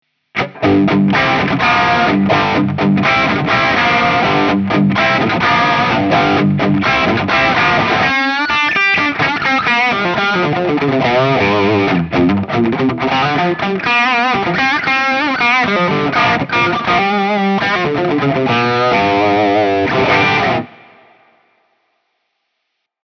Tutte le clip audio sono state registrate con amplificatore Fender Deluxe e una cassa 2×12 con altoparlanti Celestion Creamback 75.
Clip 7 – Les Paul, Max Gain, clean amp
Chitarra: Gibson Les Paul (Pickup al ponte)
Over Drive: 10/10